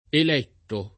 eletto [ el $ tto ]